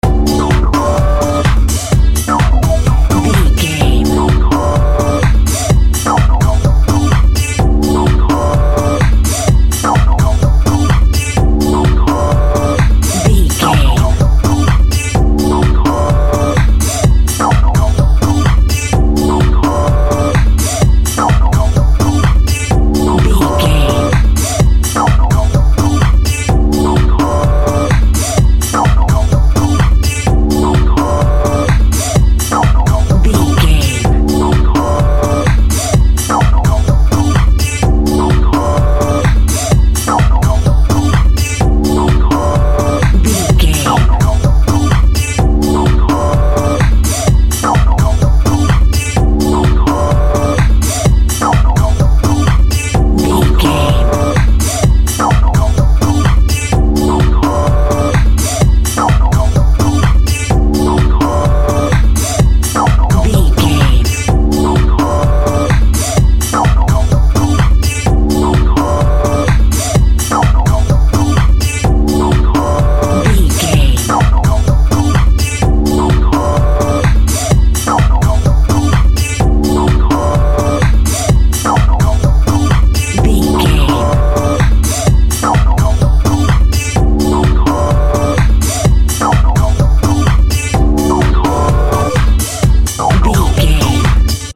Epic / Action
Fast paced
Aeolian/Minor
energetic
driving
dark
intense
drum machine
synthesiser
Drum and bass
break beat
electronic
sub bass
synth lead